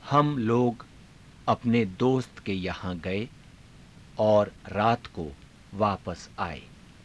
ゆっくり